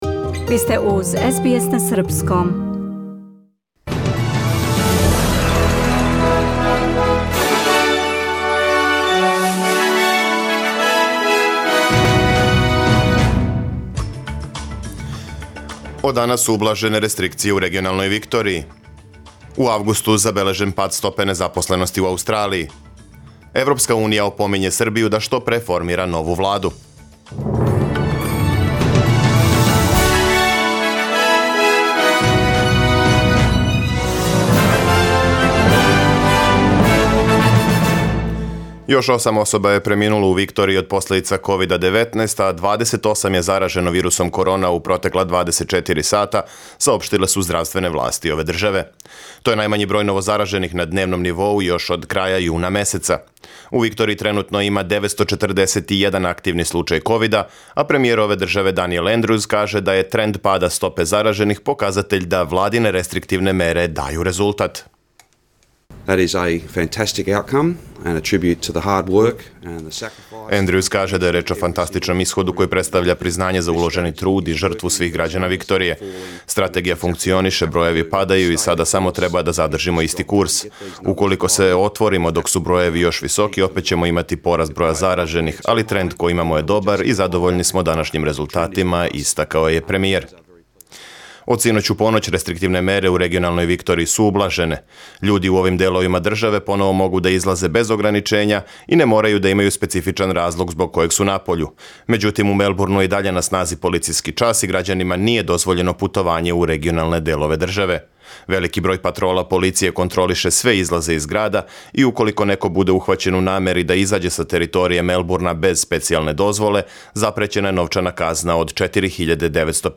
Преглед вести за 17. септембар 2020. године